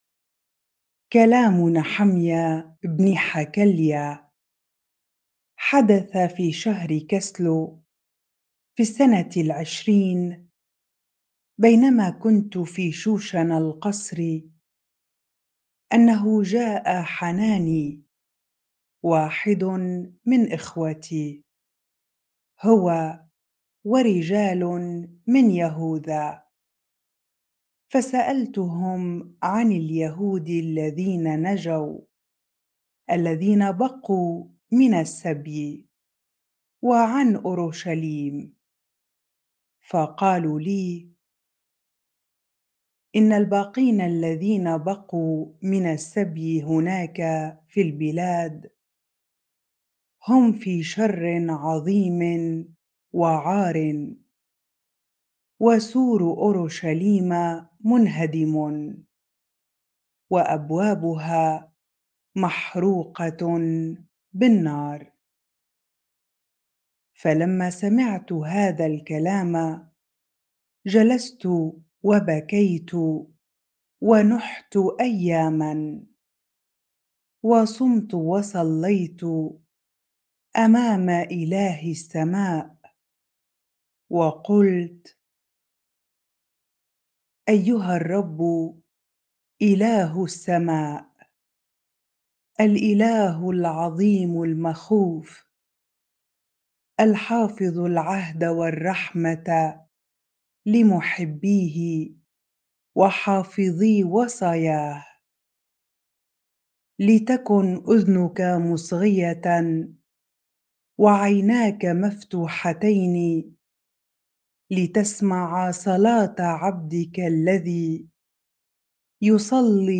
bible-reading-Nehemiah 1 ar